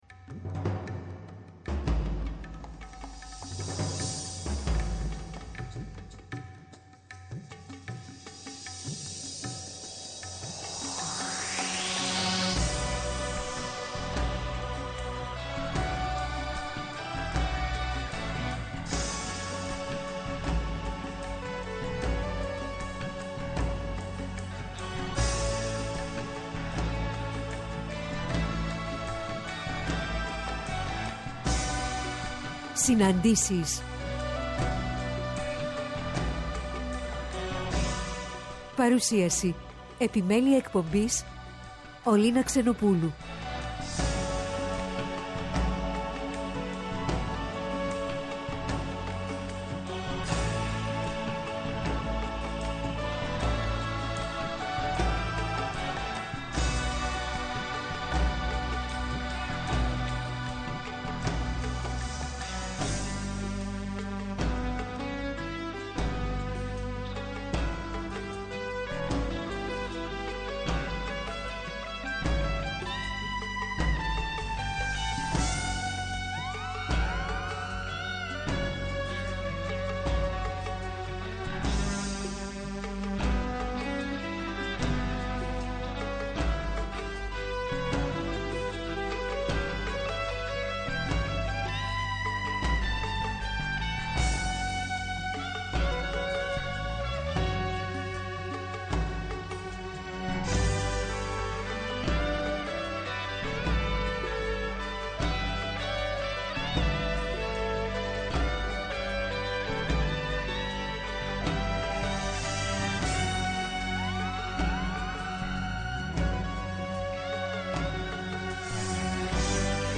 Στις «Συναντήσεις» του Πρώτου Προγράμματος σήμερα, 4-5 το απόγευμα καλεσμένη στο studio